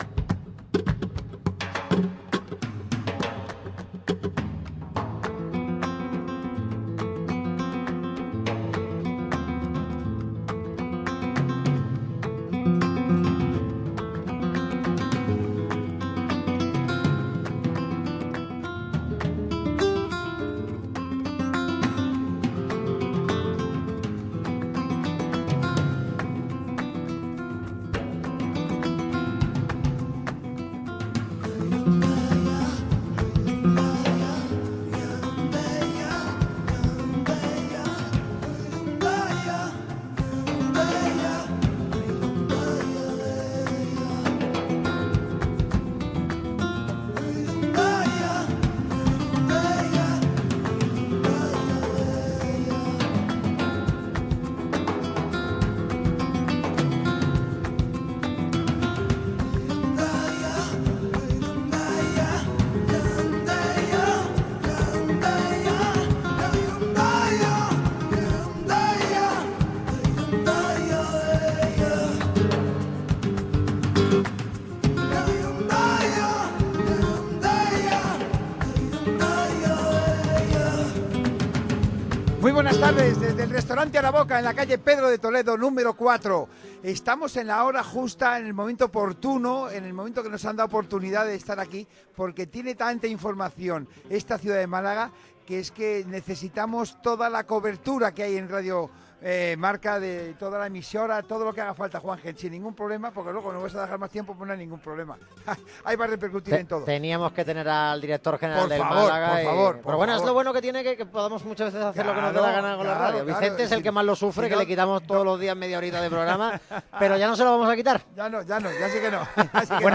Radio MARCA Málaga y Araboka, como es habitual en estas fechas, han vuelto a unirse para vivir la Feria Taurina de Málaga 2025.